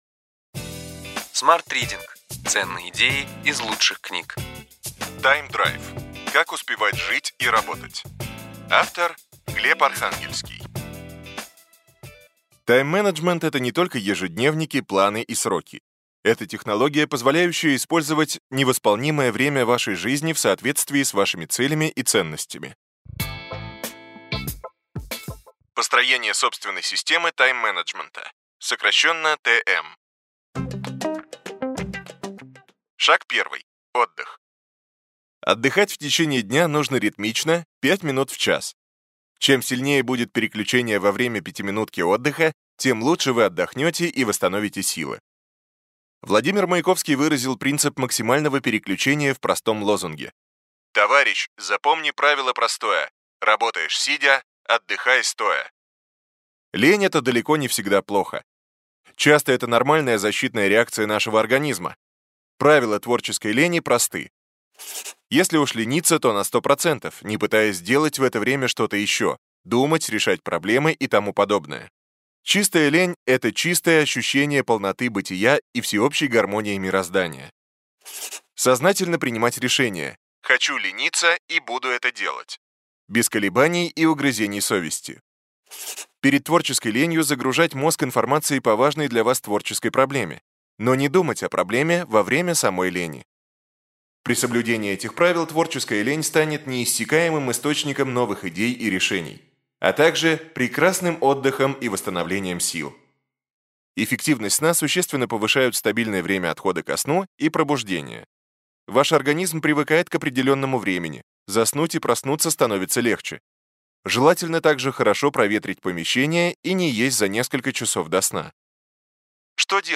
Аудиокнига Ключевые идеи книги: Тайм-драйв. Как успевать жить и работать.